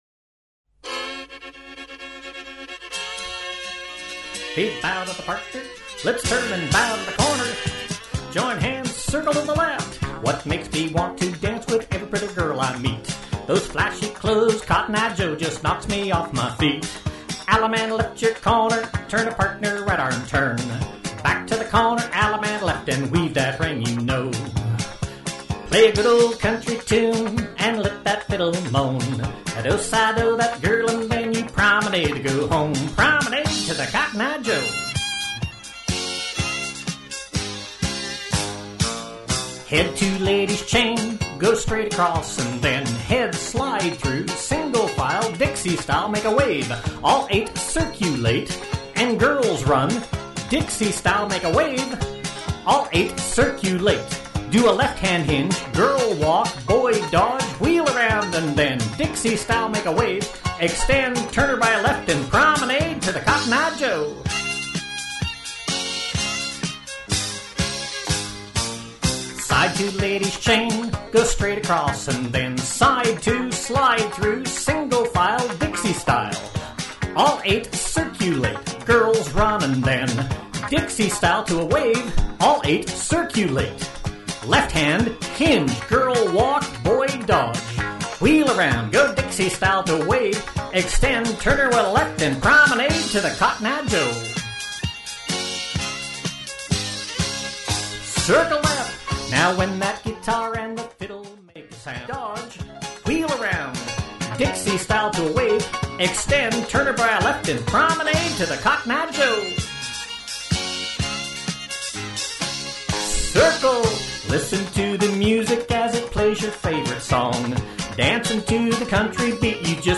Vocal Tracks
Patter Vocal